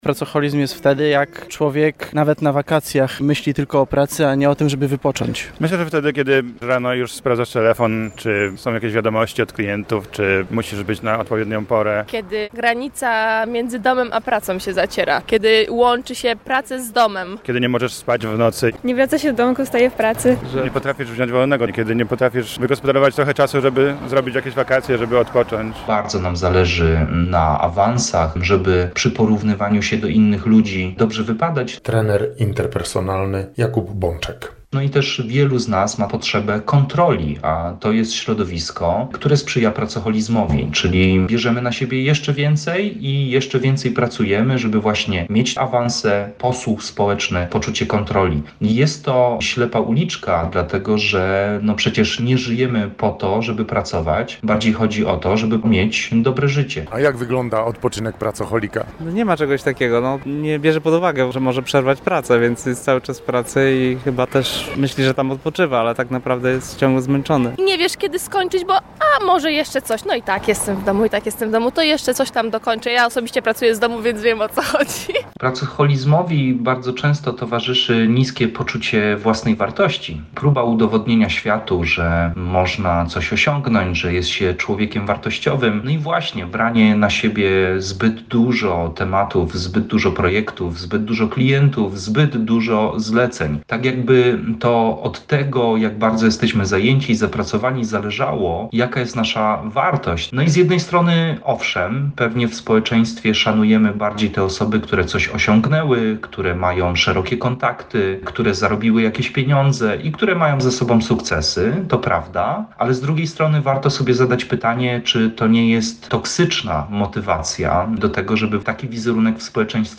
Wielu łodzian zapytanych o pracoholizm, długo zastanawiało się, zanim udzieliło odpowiedzi.